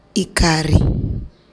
As shown in parameter 5, at least 4 distinctive levels of tonal height can be phonetically distinguished, i.e., in addition to [H] and [L] as a default realization of /H/ and /Ø/ respectively, upstepped H [ꜛH] and downstepped H [ꜜH] are identified.